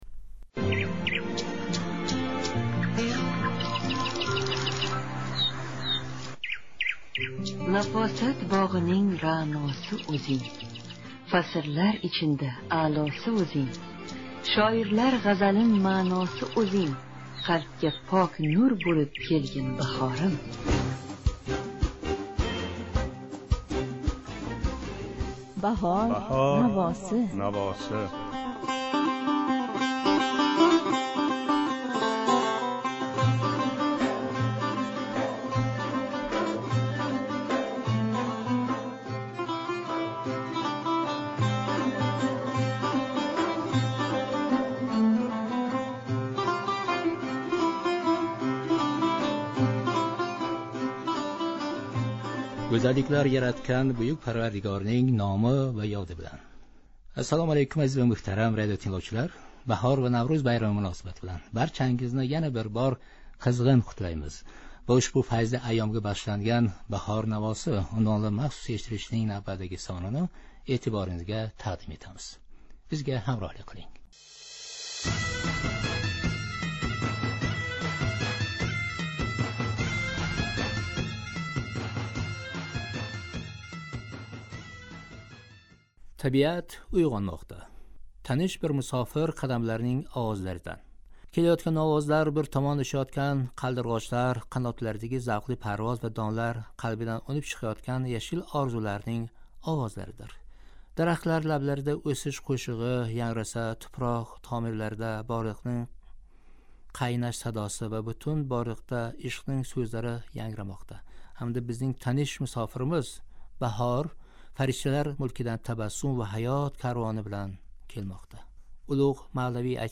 Наврўз байрамига бағишланган махсус эшиттириш